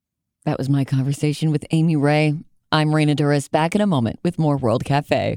(audio capture from webcast)
08. introduction (0:05)